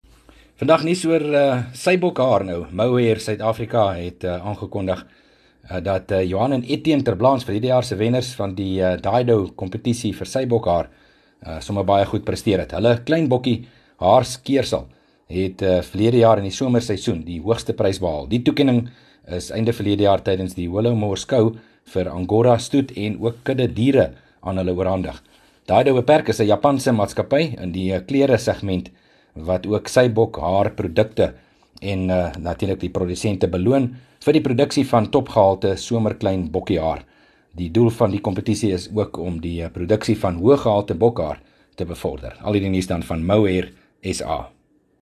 23 Feb PM berig oor sybokhaar presteerders